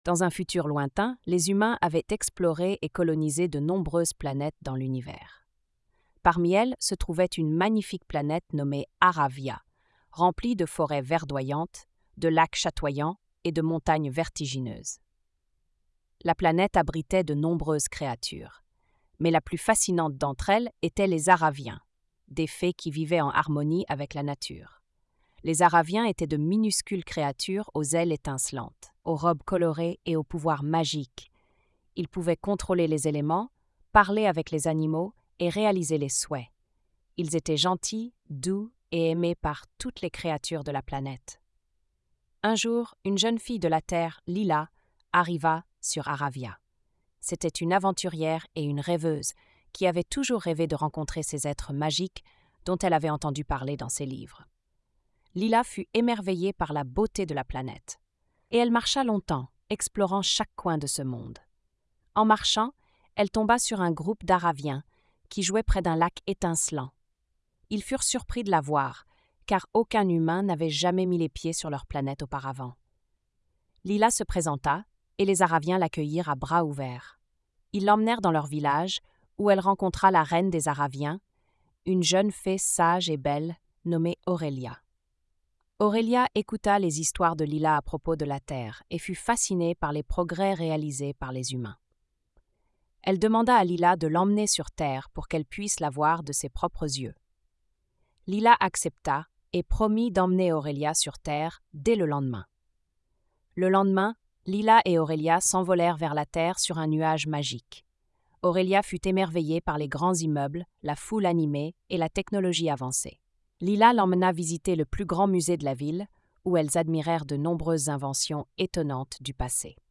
🎧 Lecture audio générée par IA